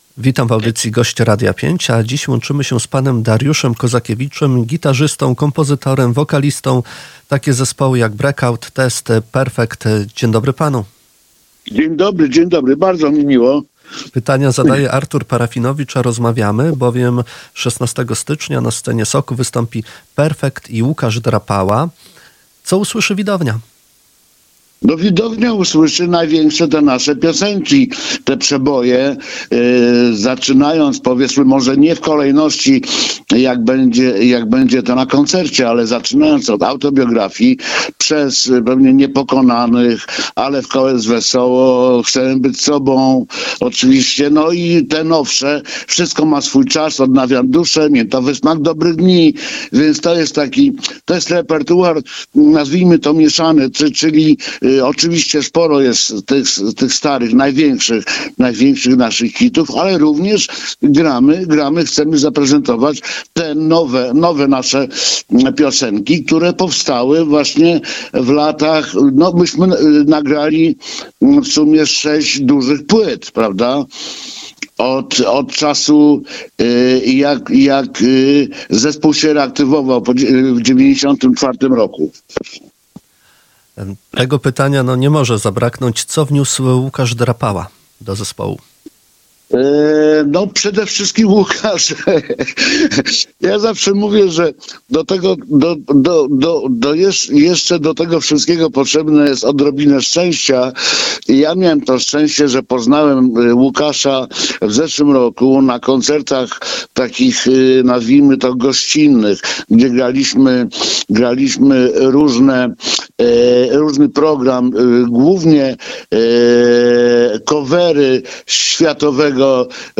O zbliżającym się wydarzeniu mówił w Radiu 5 Dariusz Kozakiewicz, gitarzysta i kompozytor Perfectu.
Wspominał koncert sprzed 23 lat w ramach Lata z Radiem 5 i Suwałki Blues Festival. Cała rozmowa poniżej.